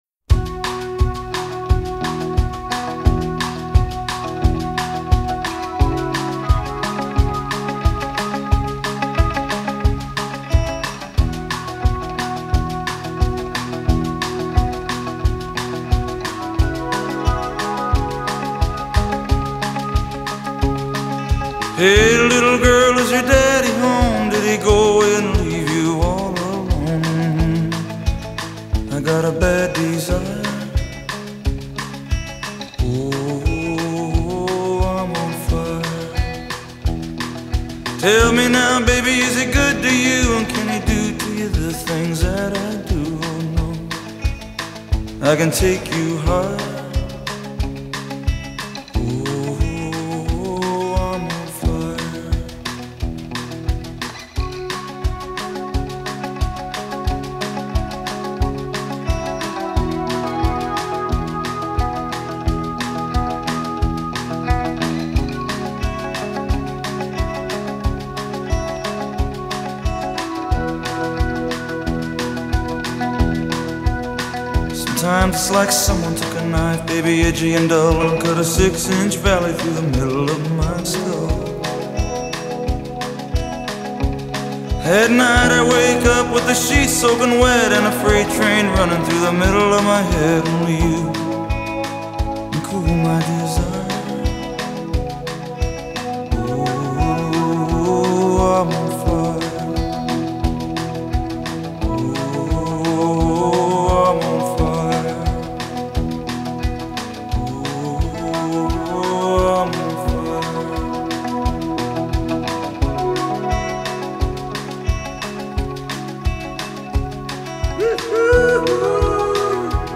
Background  Vocal, Piano, Synthesizer
Percussion
Glockenspiel, Organ
Bass
Acoustic  Guitar, Background  Vocal, Mandolin
Drums